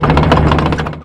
tank-engine-load-rotation-4.ogg